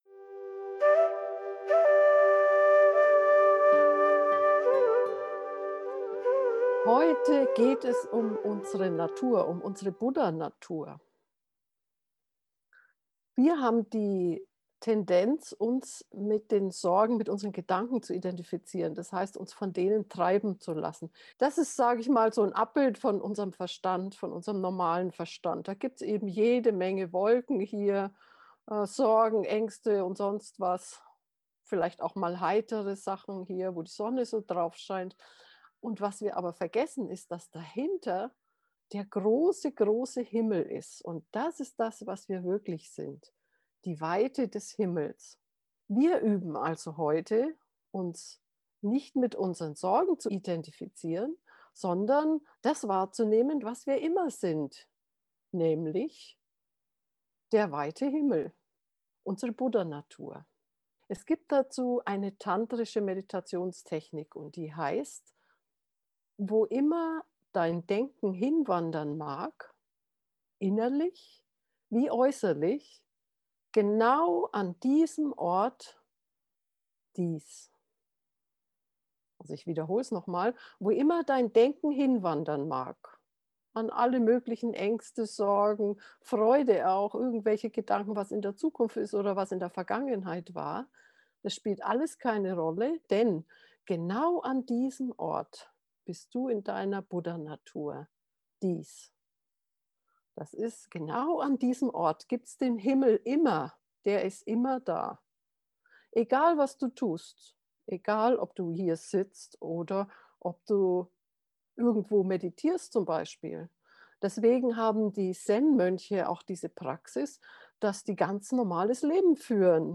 Wenn du unter bedrückenden Gedanken leidest und vielleicht noch deine Buddha-Natur entdecken möchtest, dann ist diese geführte Alltags-Meditation das Richtige für dich.
gedanken-himmel-buddhanatur-gefuehrte-meditation